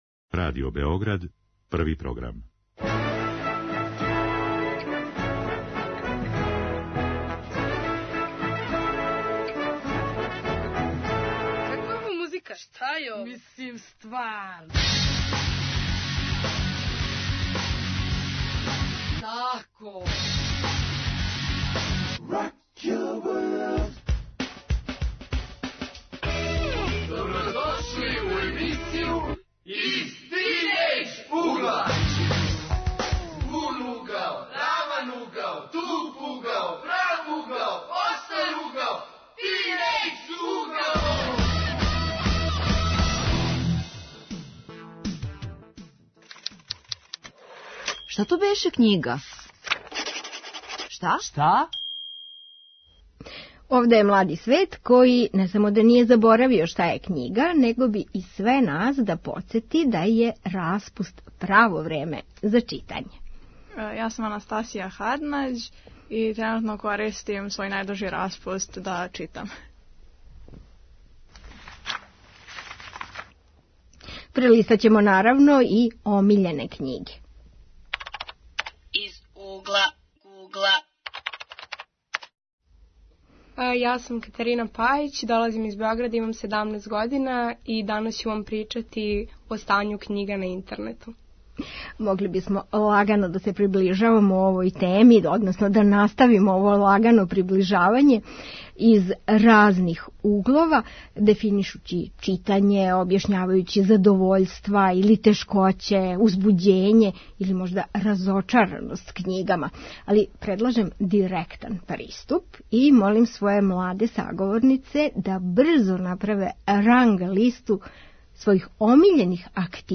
Време је за читање, кажу тинејџери и на суботње дружење доносе књиге у којима уживају овог лета.